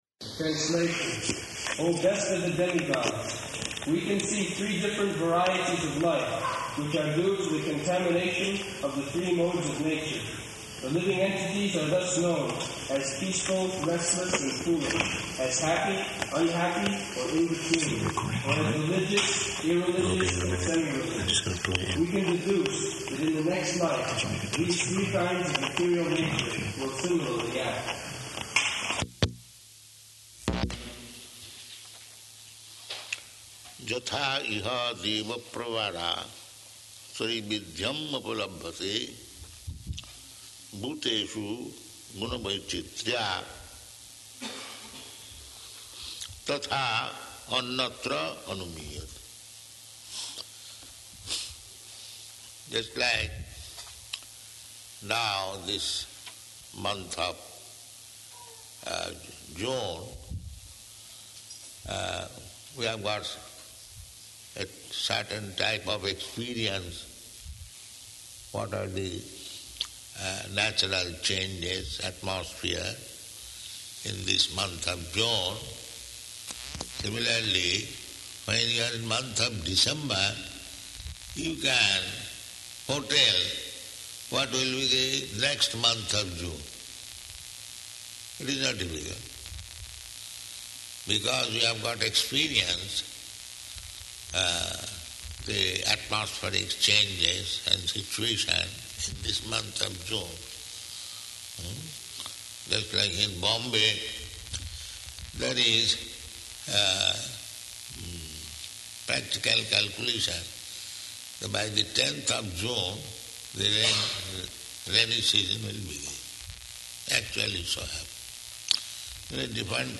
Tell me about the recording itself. Location: Detroit